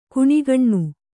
♪ kuṇigaṇṇu